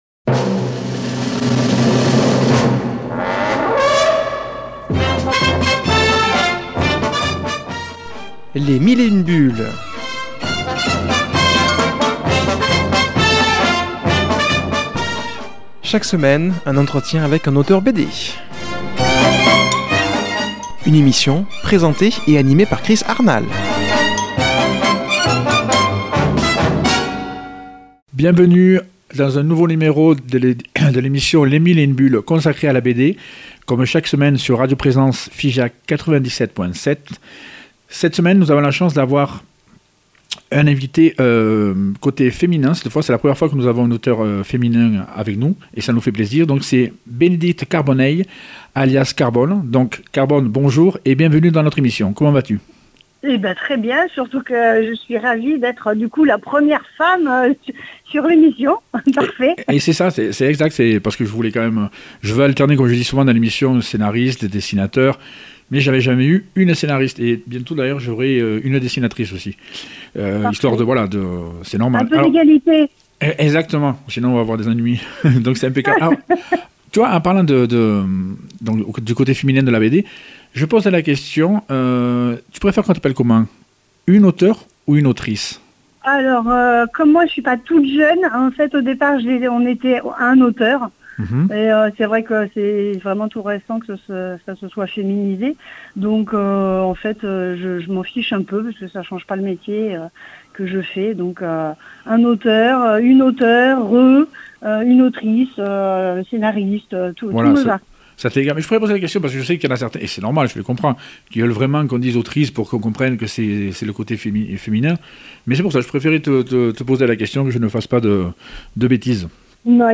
qui a comme invitée au téléphone